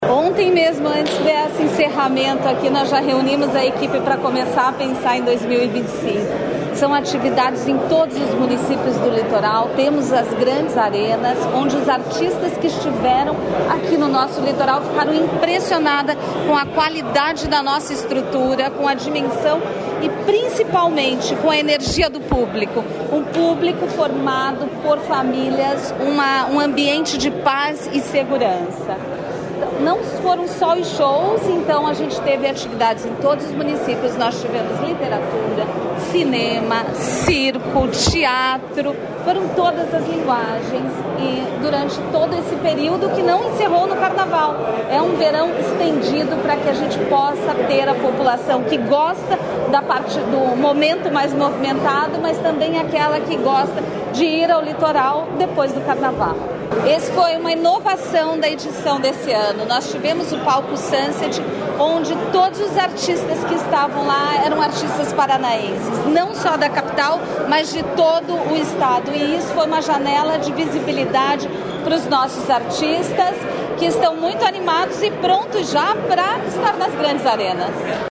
Sonora da secretária da Cultura, Luciana Casagrande Pereira, sobre o balanço do Verão Maior Paraná 2023/2024